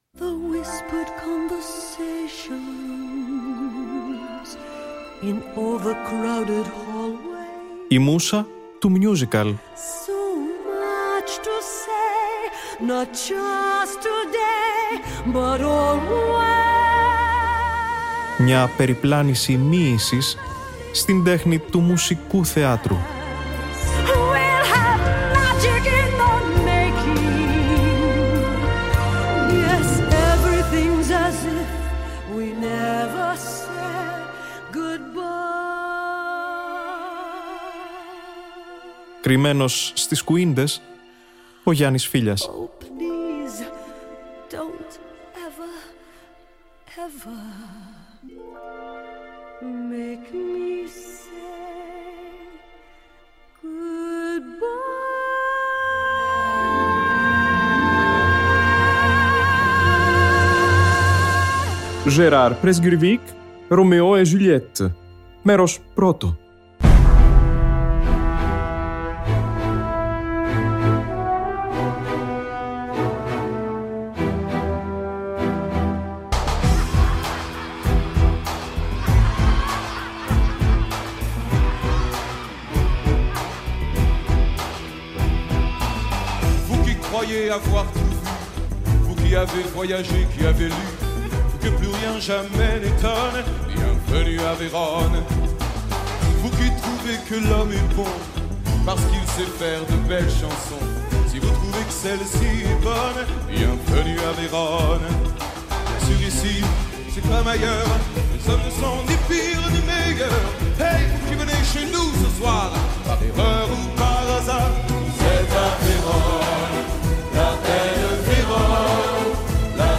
Γαλλικό musical